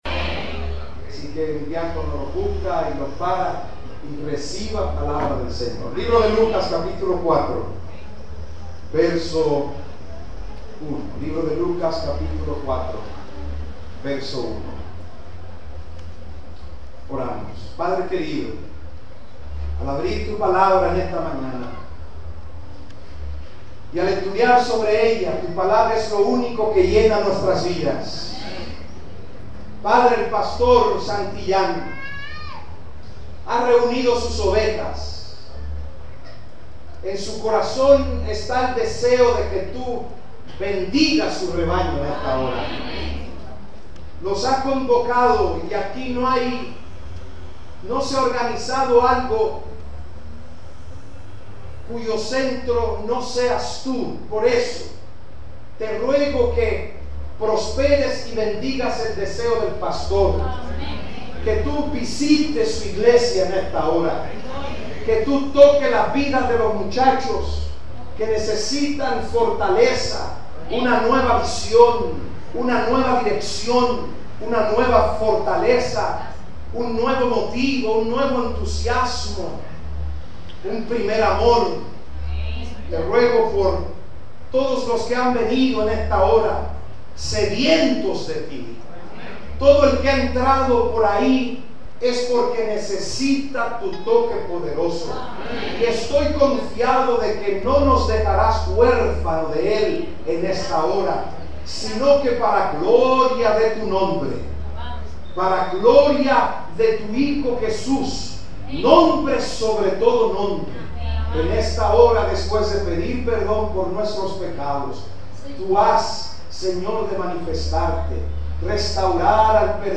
predicando en la iglesia adventista la Esperanza